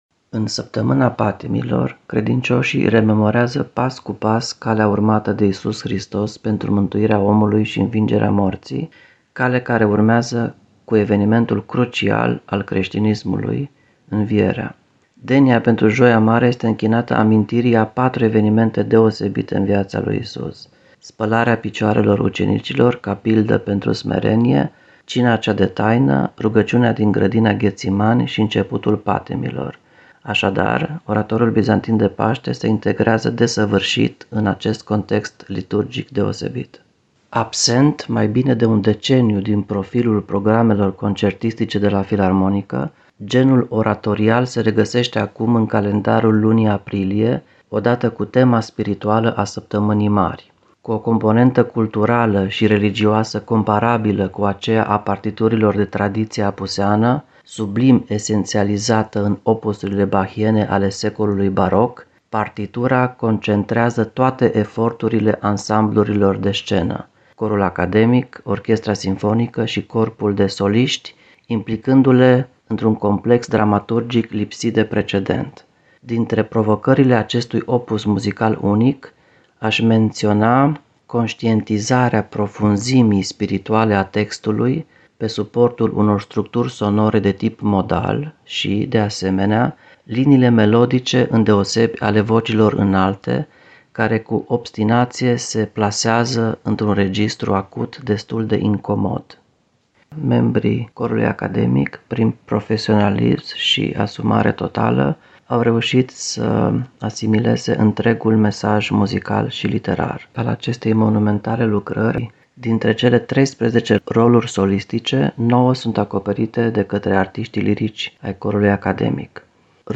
Interviu în exclusivitate pentru Radio Timişoara